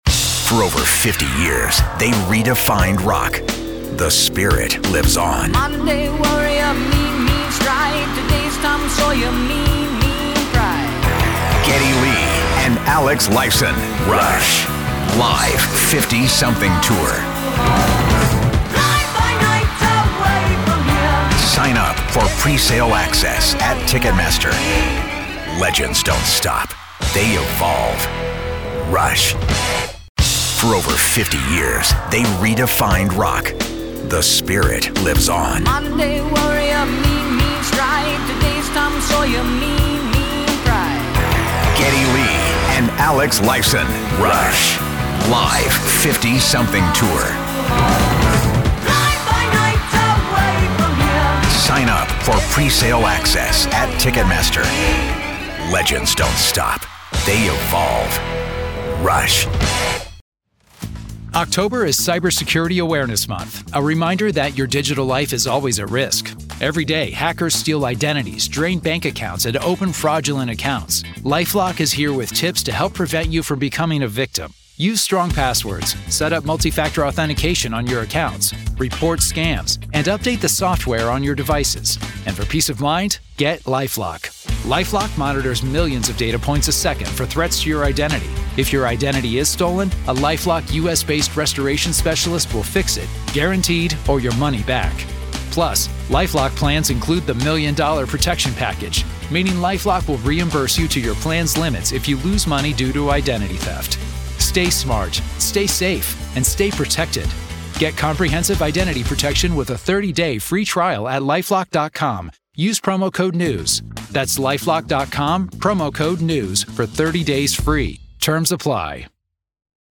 We play the raw footage, allowing you to see and hear for yourself how Murdaugh describes the horrific discovery.